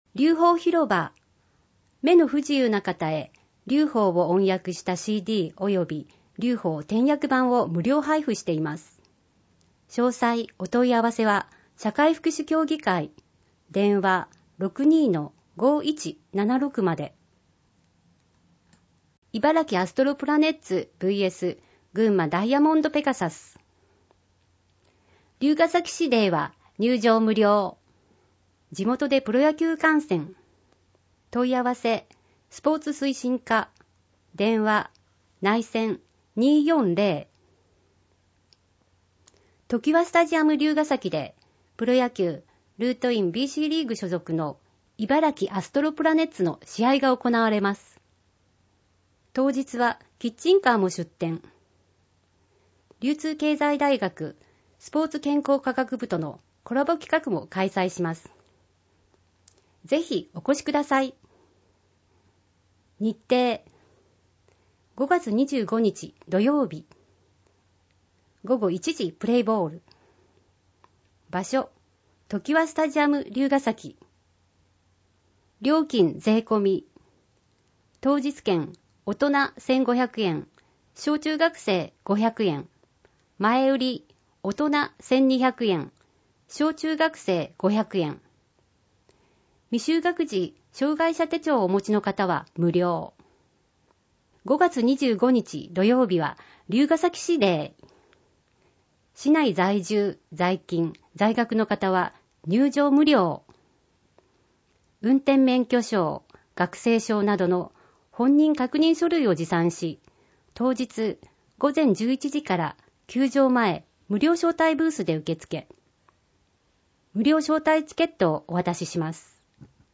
『りゅうほー』の音訳CD・点訳版を配布しています